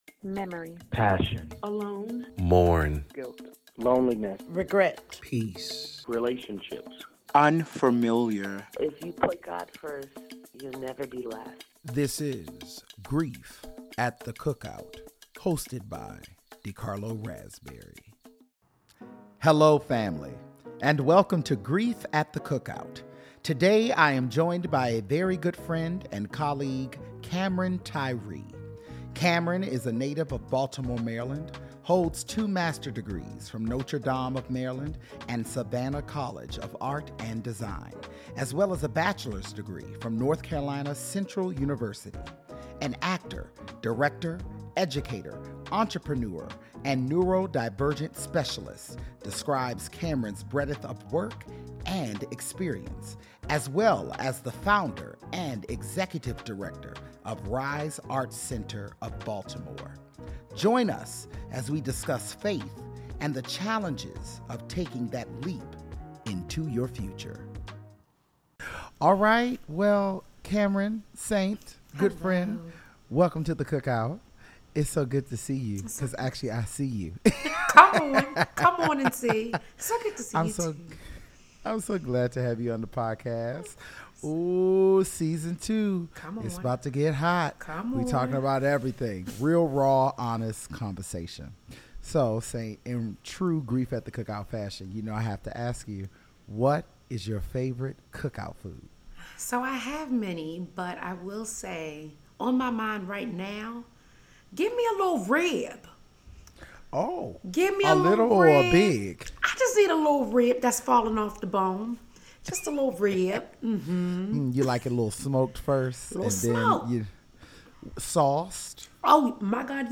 Real, Honest, Raw Conversation...